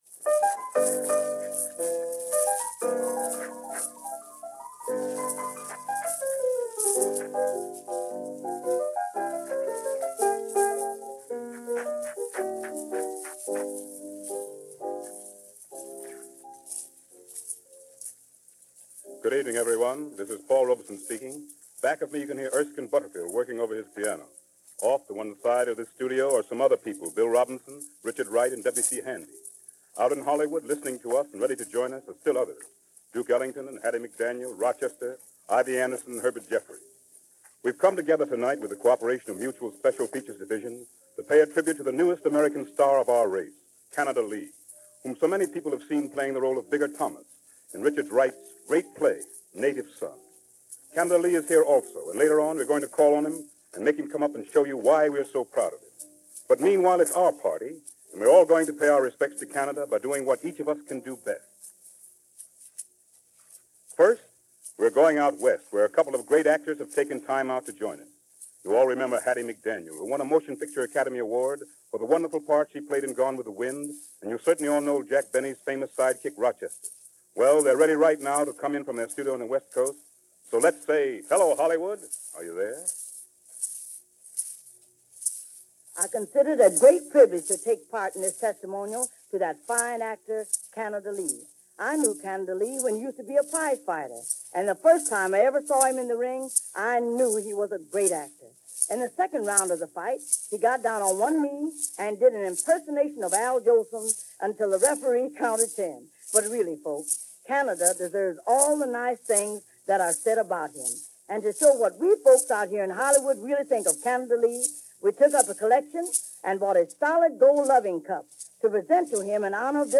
This program was a celebration of the Broadway opening for the Charles Wright play Native Son, which Orson Welles also produced and which Lee had the starring role of Bigger Thomas.